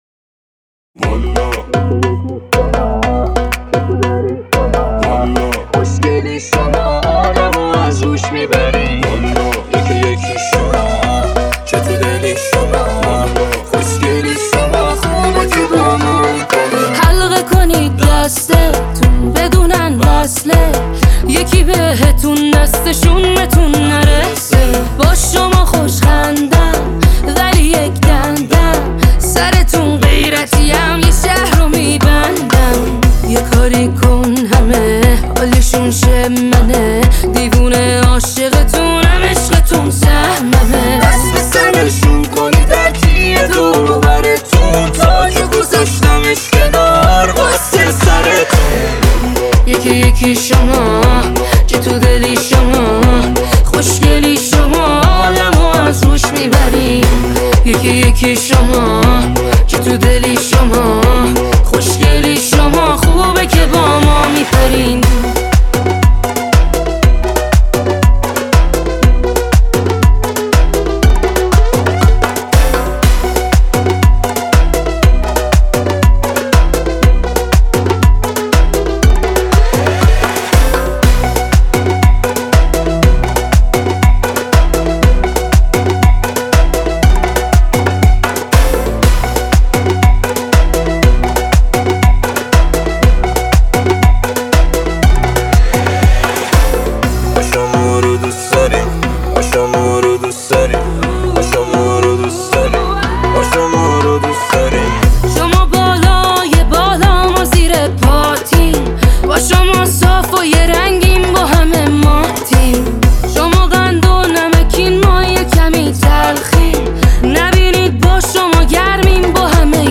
Download New Remix BY
ریمیکس